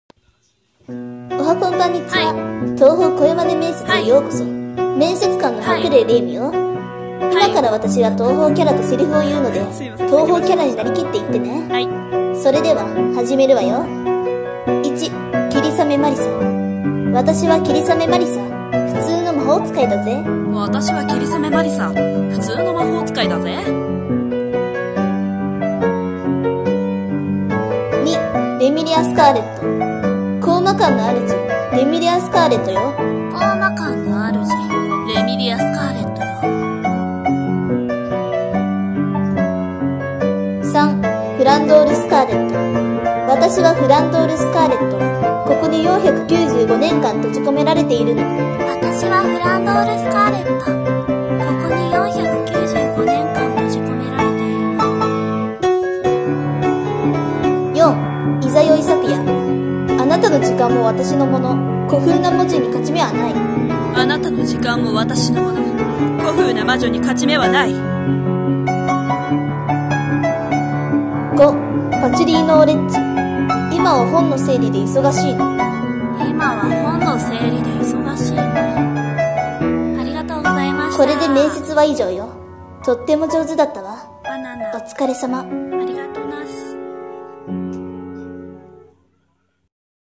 東方声真似面接 面接官:博麗霊夢 受験者:名前を記入